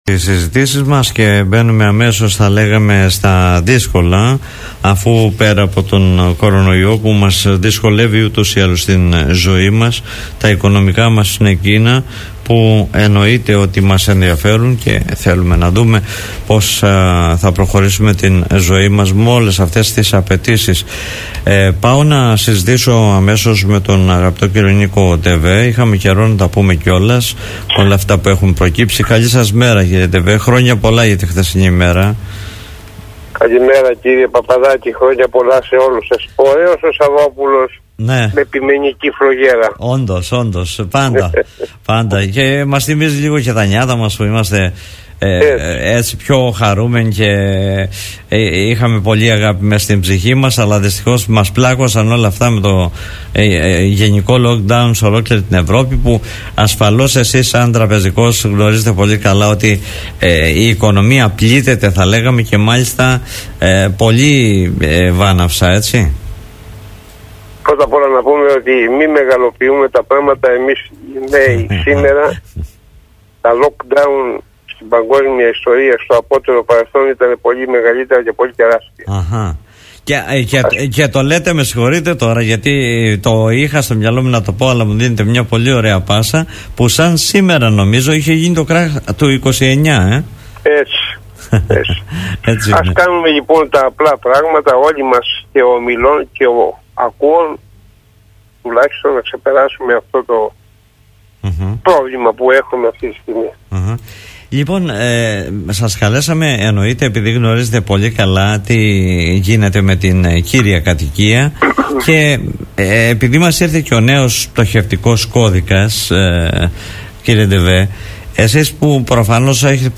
μιλώντας στον Politica 89.8